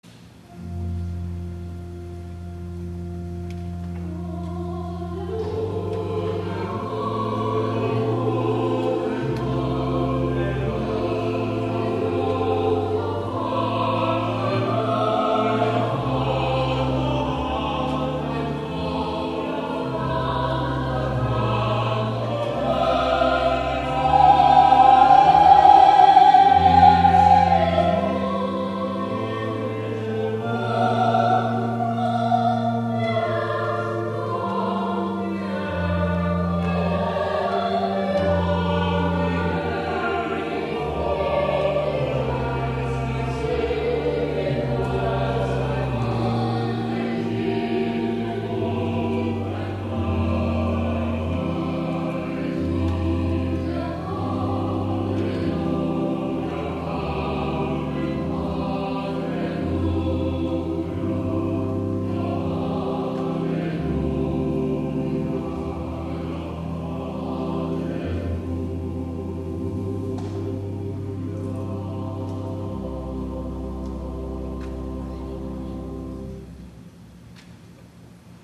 Pentecost
*THE CHORAL RESPONSE